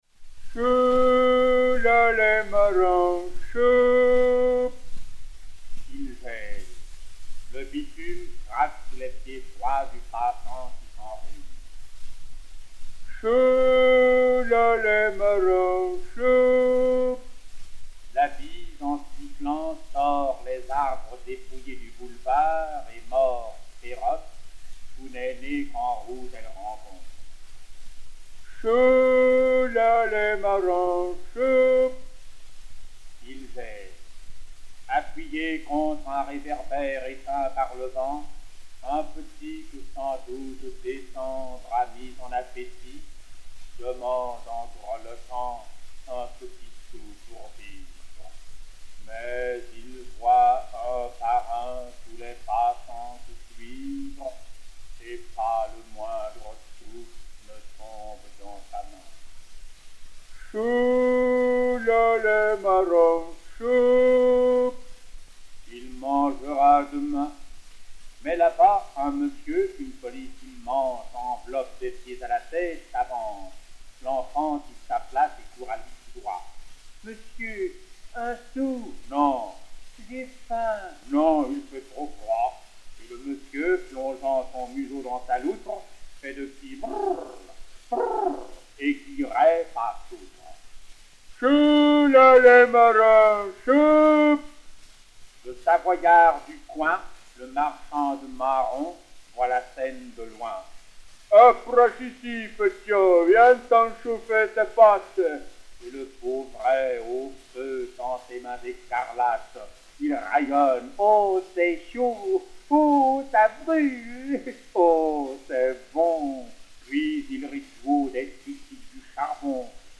poésie d'Ernest Grenet-Dancourt
Firmin Gémier du Théâtre Antoine
Disque Pour Gramophone G.C.-31358, mat. 17219u, enr. à Paris le 13 mai 1912